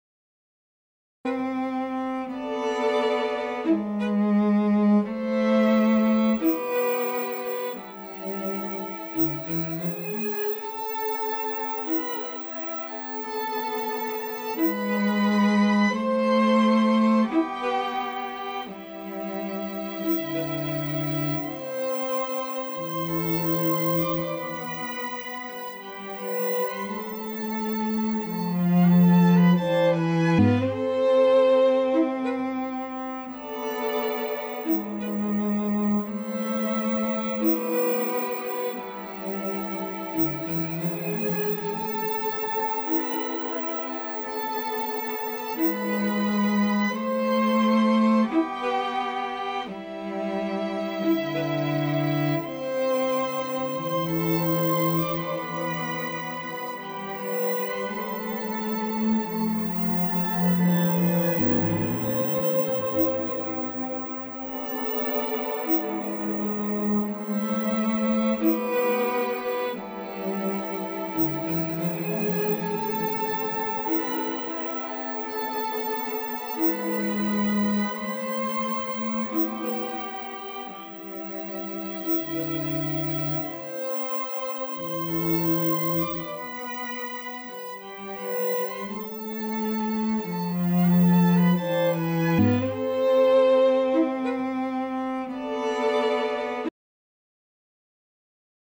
From the first digital reverb. Very deep.